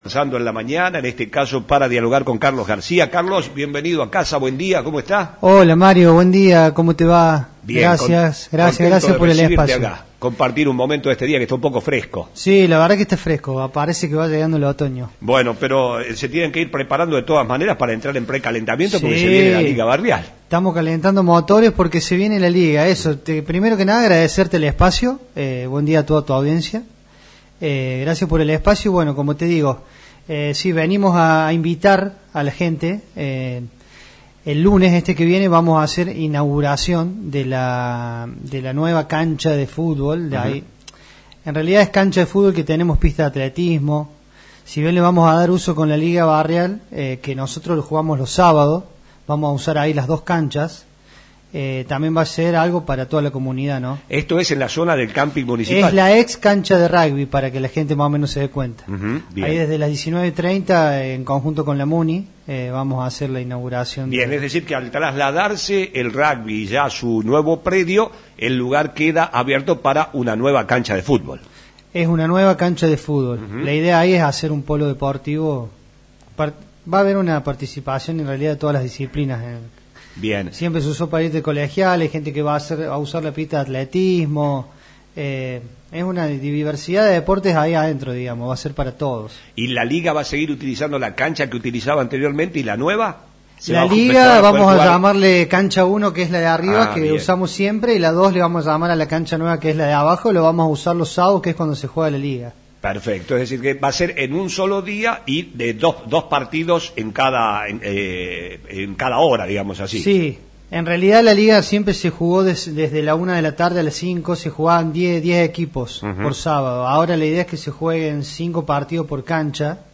visitó nuestros estudios para invitar a la audiencia a conocer la nueva cancha y el nuevo sistema de iluminación para el arranque de un nuevo campeonato de la Liga Barrial. Serán 32 equipos de 25 jugadores cada uno lo que demuestra el avance que ha tenido con el paso de los años.